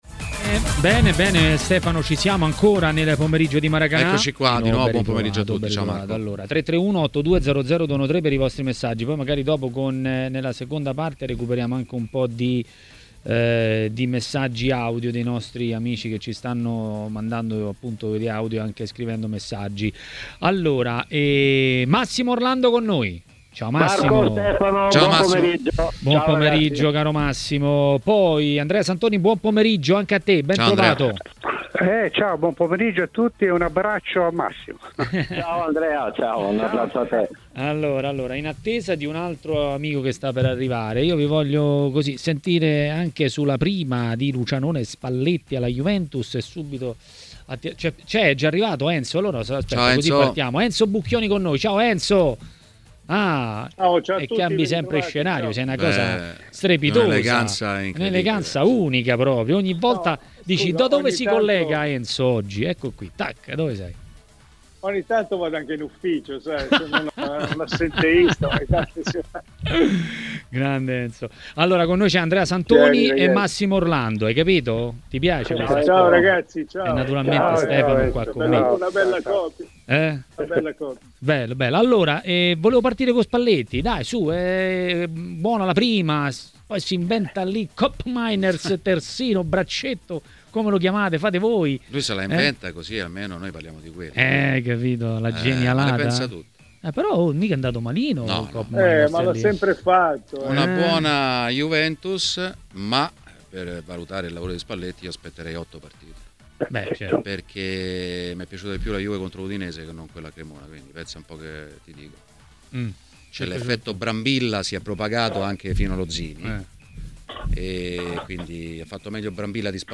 A TMW Radio, durante Maracanà, è intervenuto l'ex calciatore e commentatore tv Antonio Di Gennaro.